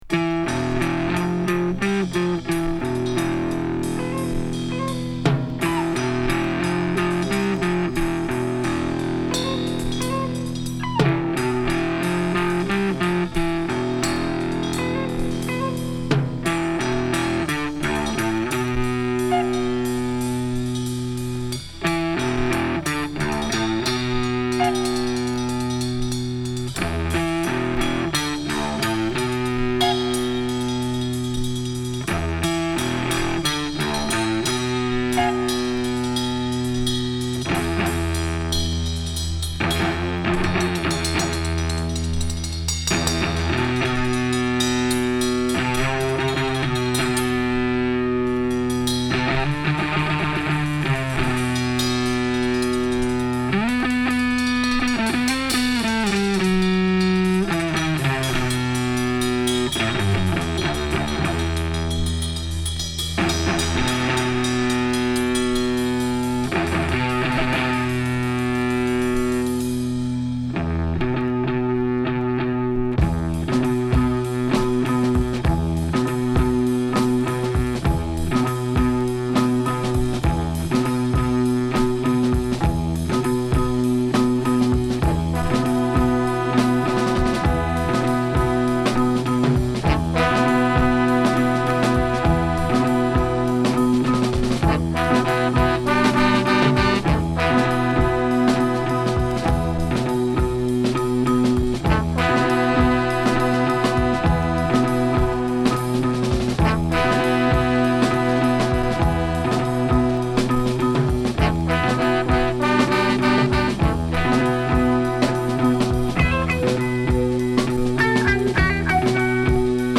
疾走感溢れる前半から一転、クソドープなロウ ファンク〜レアグルーヴへと展開するA3
土着的なリズムから、アフロ/レアグルーヴな展開を魅せるB2